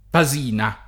[ pa @& na ]